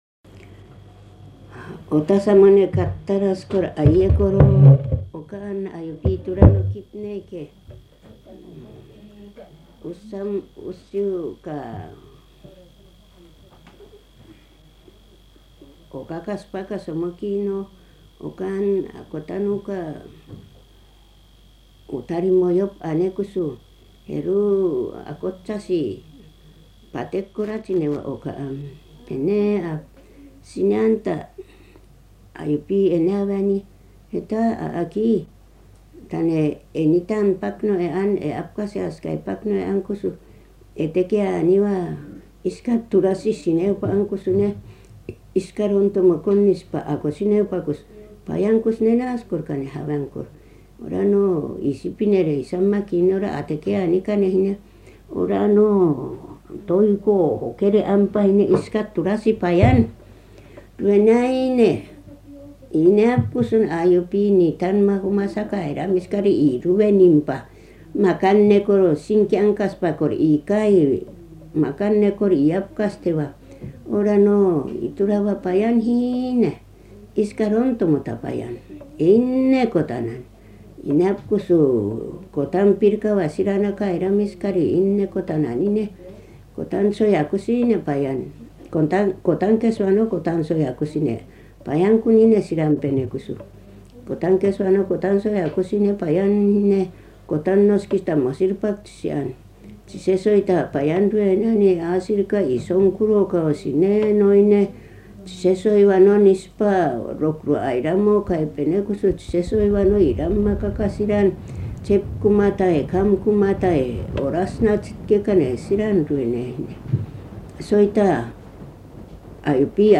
[10-1 散文説話 prose tales] アイヌ語音声 22:26